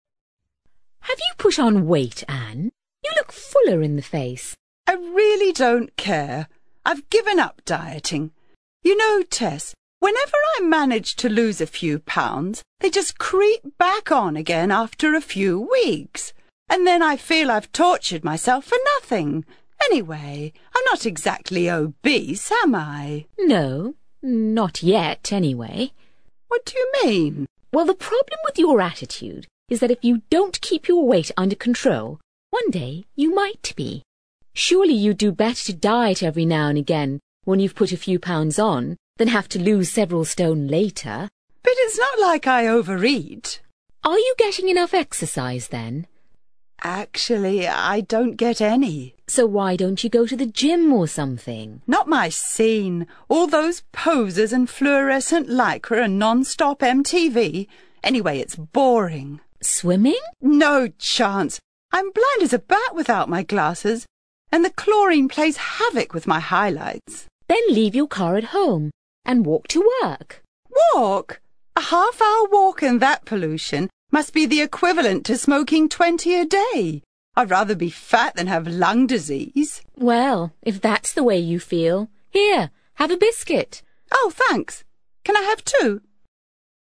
ACTIVITY 70: You are going to hear two friends having a conversation about slimming.